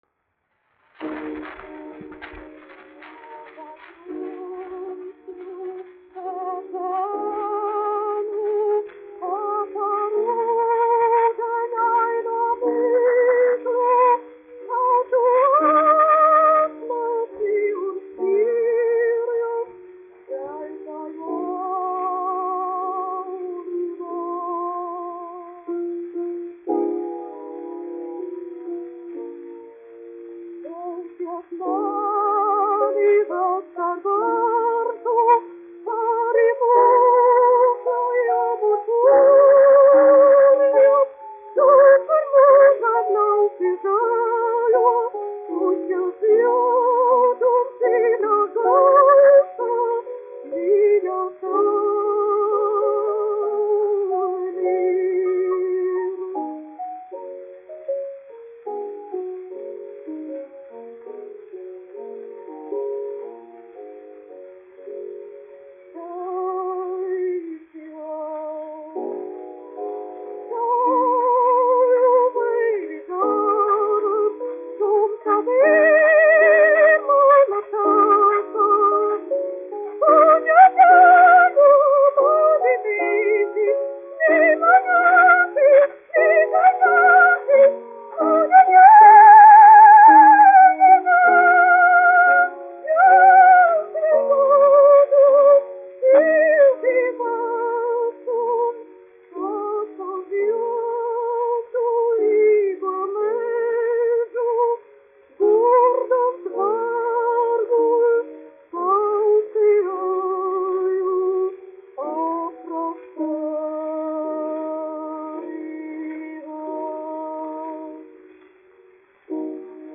1 skpl. : analogs, 78 apgr/min, mono ; 25 cm
Dziesmas (vidēja balss) ar klavierēm
Skaņuplate
Latvijas vēsturiskie šellaka skaņuplašu ieraksti (Kolekcija)